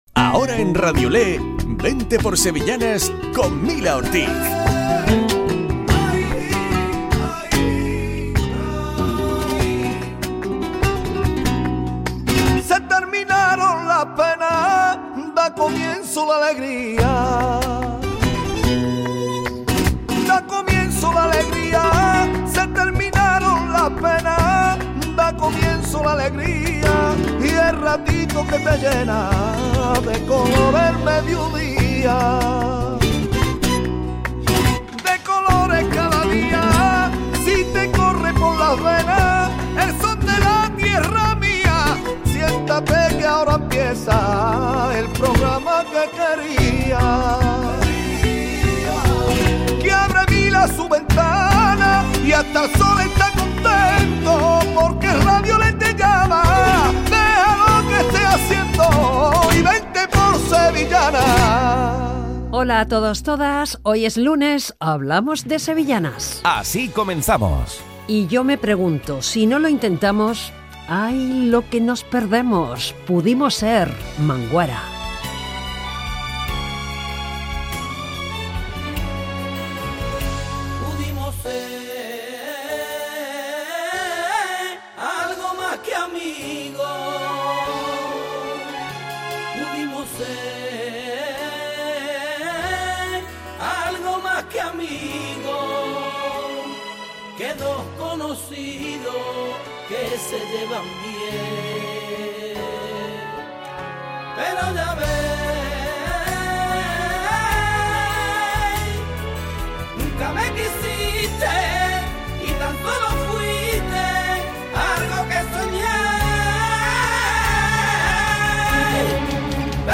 Programa dedicado a las sevillanas. Hoy recordando ‘El amor no tiene edad’.